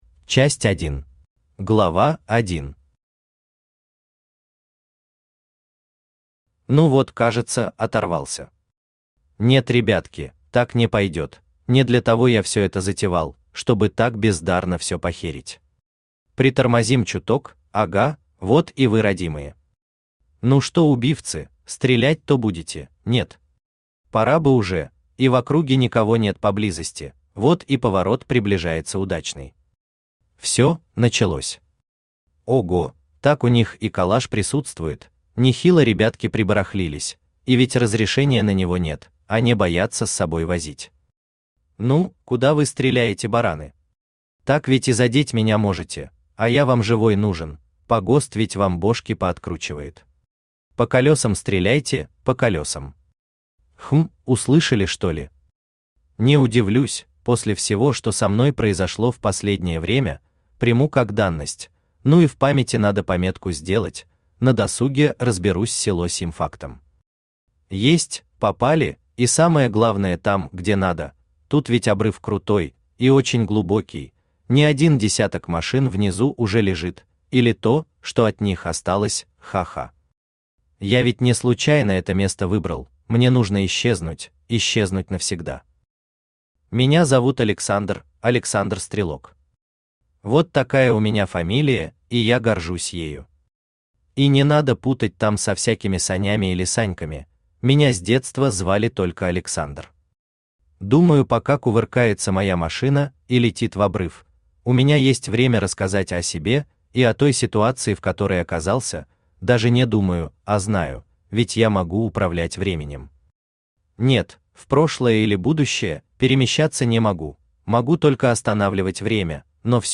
Аудиокнига Есть только миг…
Автор Данияр Акбарович Гафаров Читает аудиокнигу Авточтец ЛитРес.